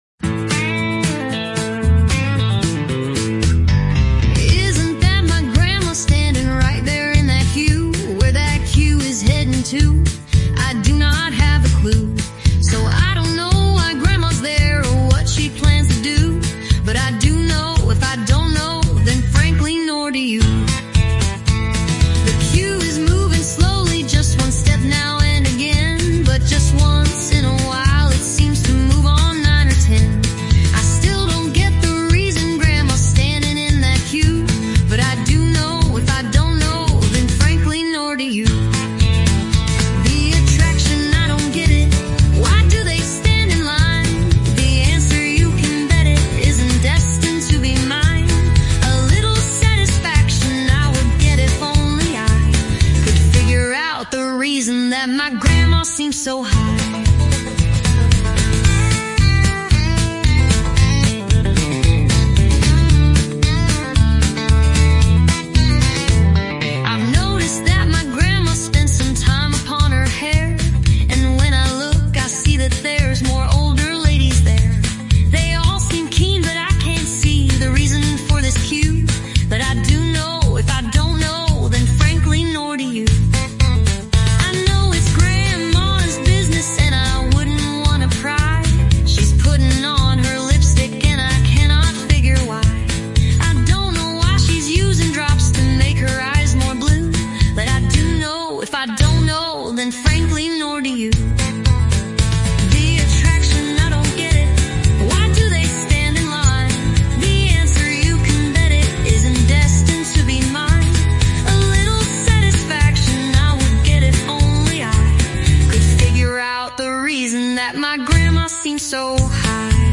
Rewritten so the robots can put it to music.